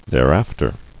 [there·af·ter]